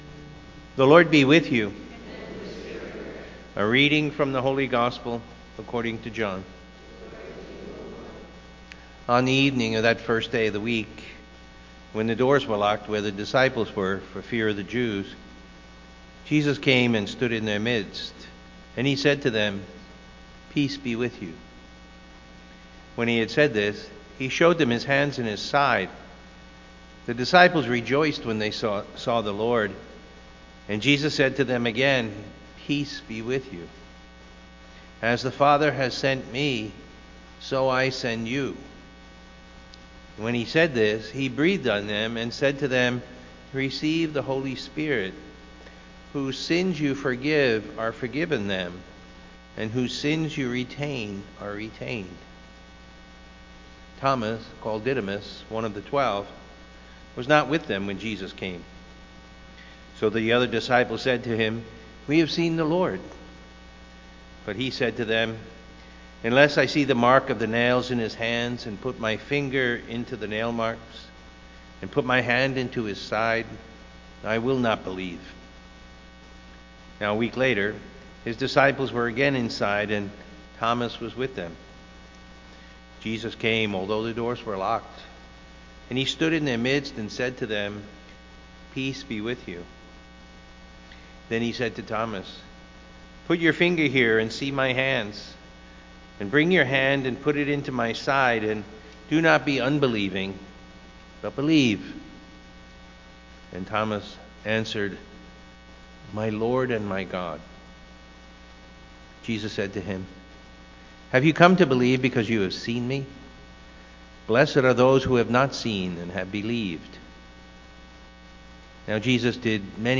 Looking to go deeper in your prayer life? Listen to the homily from the Sunday Mass and meditate on the Word of God.